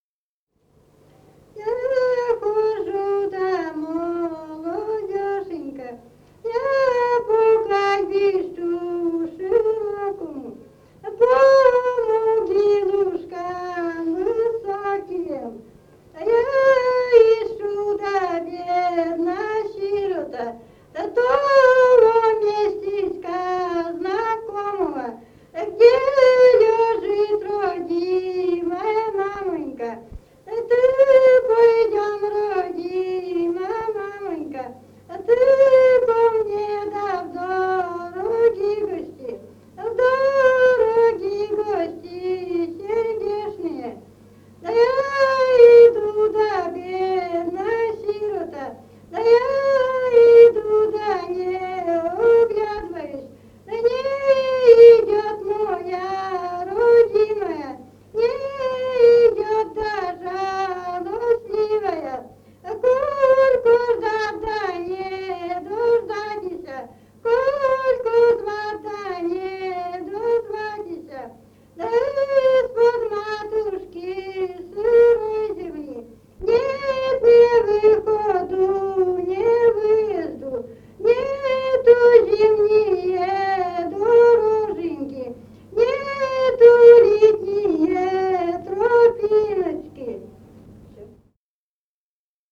«Я хожу да молодёшенька» (причитания невесты).
в д. Малата Череповецкого района, 28 декабря 1954 года